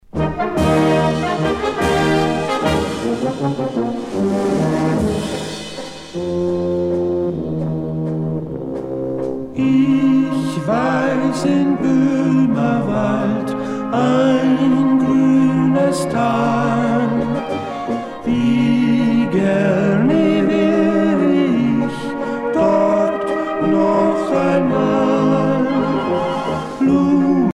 valse lente
Pièce musicale éditée